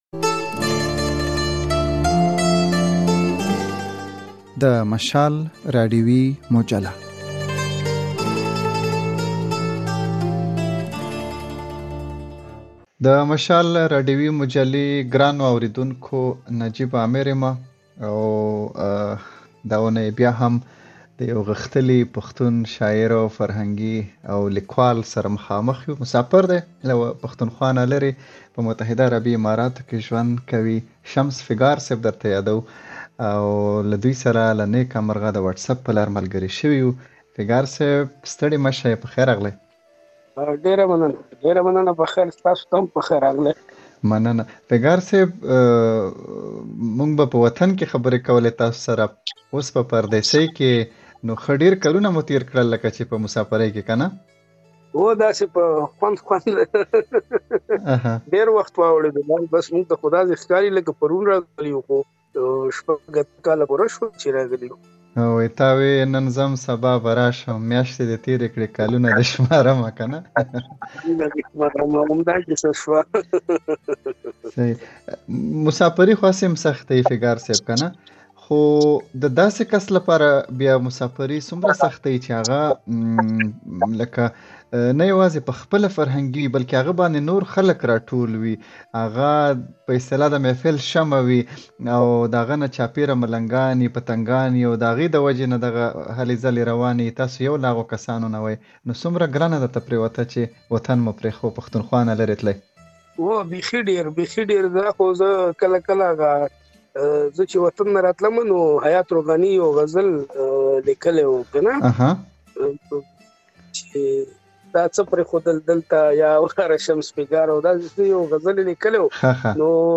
نوموړی وايي، لکه څنګه چې یې په خپله پلرنۍ سیمه کې د ادب پالنه کوله، هماغسې یې په پردیسۍ کې هم خپل کار او زیار جاري ساتلی دی. د خپرونې په اوږدو کې د هغوی شاعري هم اورېدلی شئ.